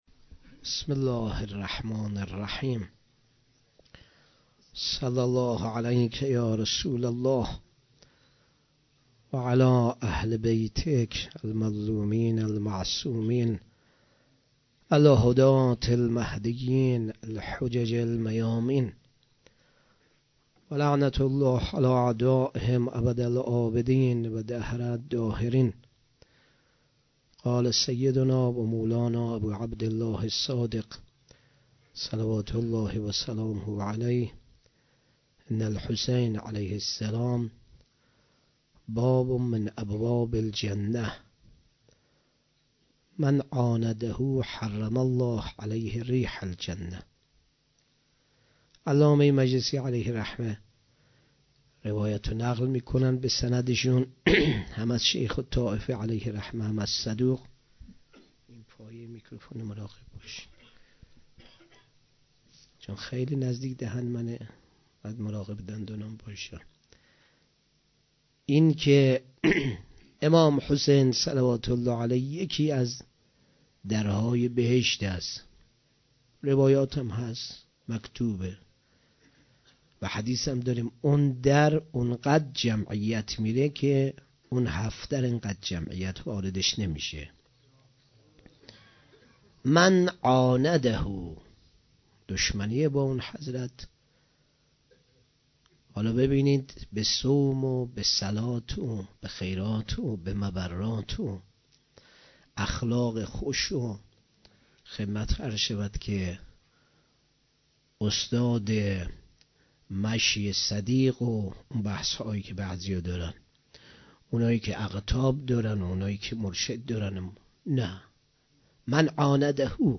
5 صفر 96 - غمخانه بی بی شهربانو - سخنرانی